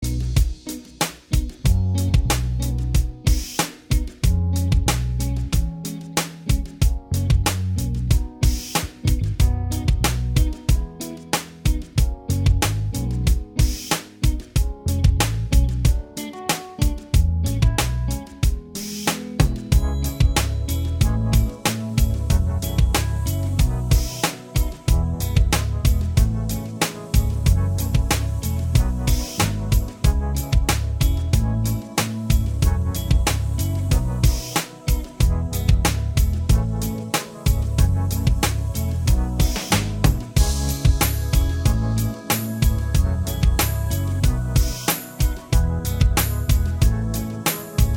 Minus Main Guitar Soft Rock 5:30 Buy £1.50